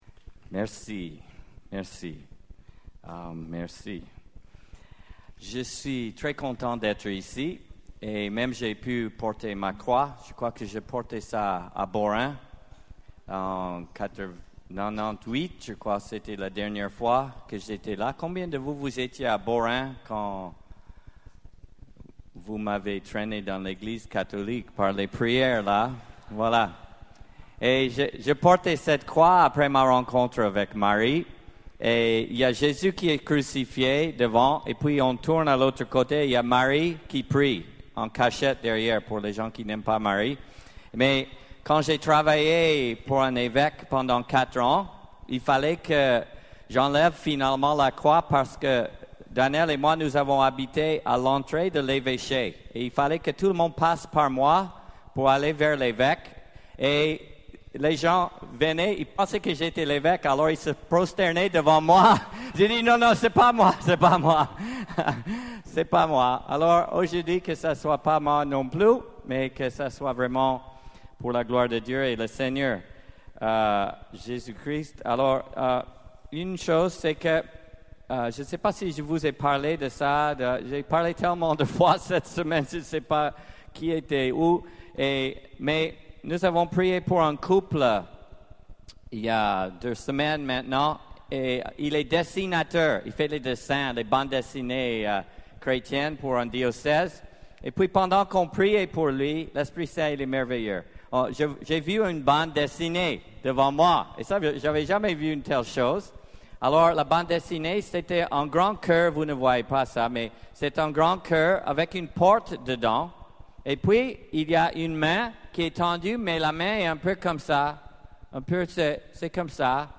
(Banneux Session charismatique 21-25 juillet 2009)